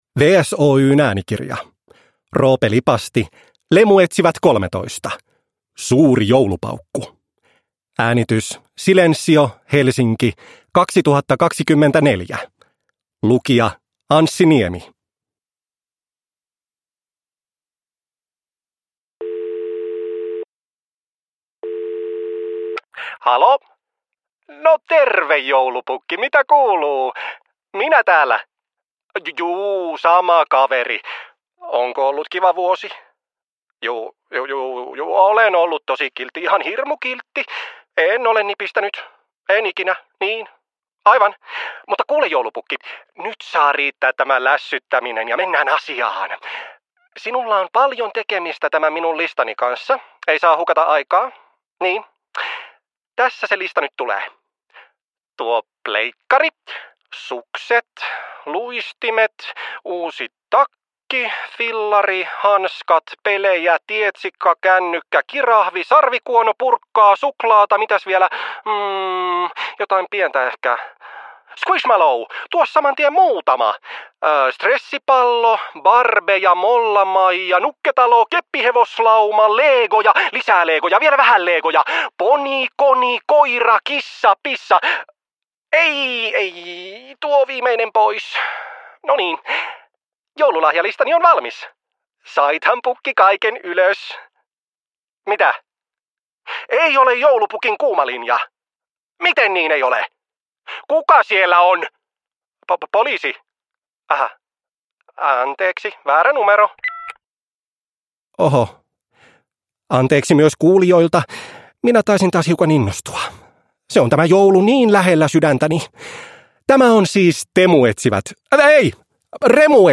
Lemuetsivät 13: Suuri joulupaukku (ljudbok) av Roope Lipasti